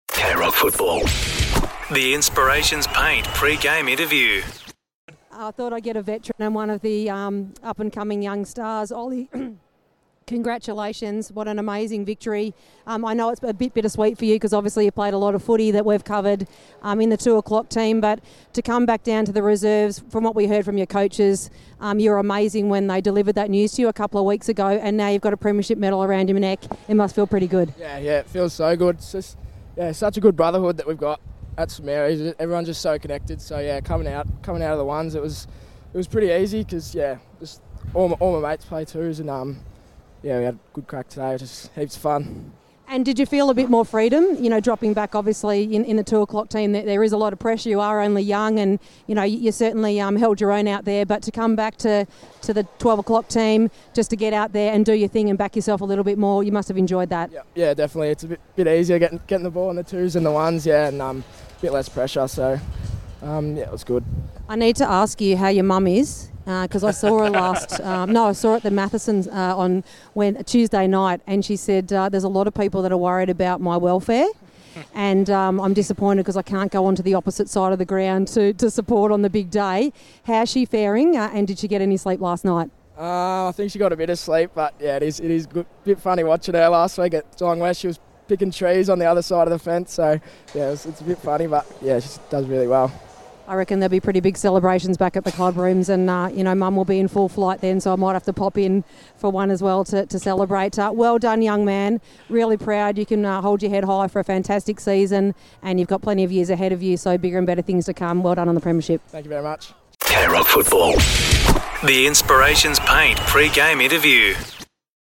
2025 - GFNL - Grand Final - St Joseph's vs. St Mary's - Pre-match interview